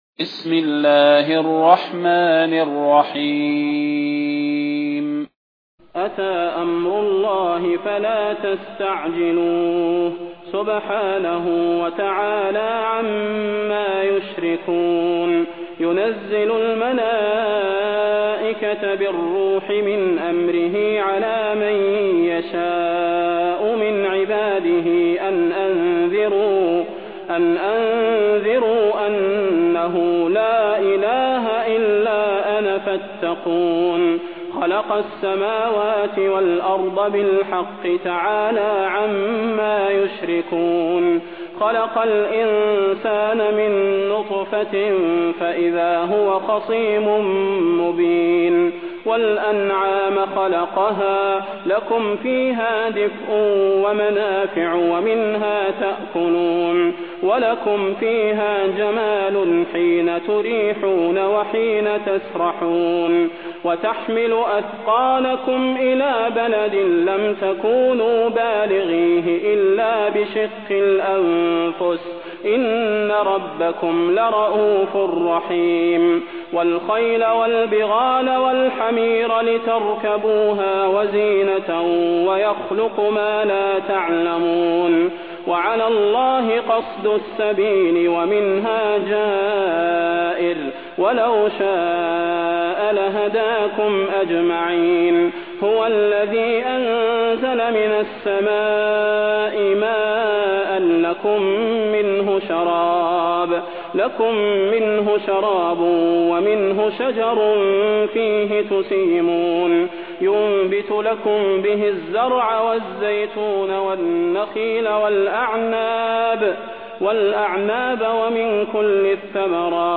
تلاوة سورة النحل بصوت الشيخ صلاح بن محمد البدير
المكان: المسجد النبوي الشيخ: فضيلة الشيخ د. صلاح بن محمد البدير فضيلة الشيخ د. صلاح بن محمد البدير سورة النحل